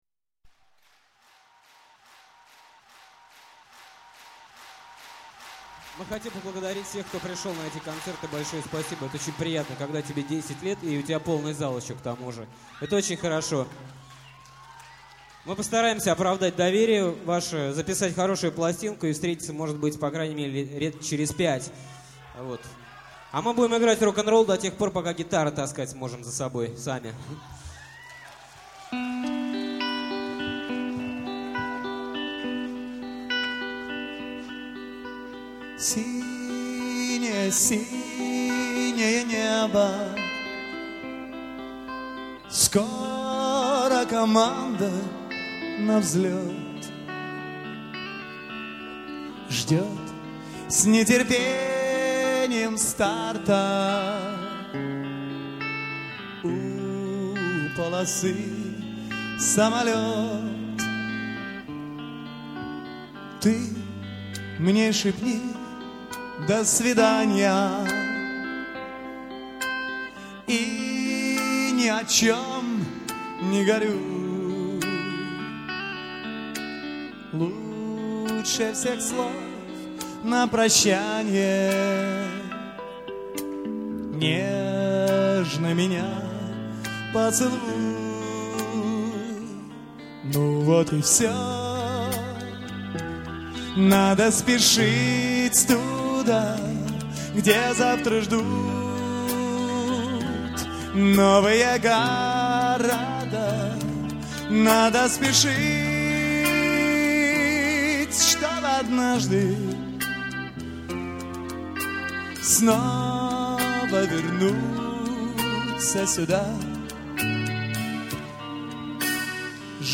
задумчивая гитара, теплый саксофон и уютный голос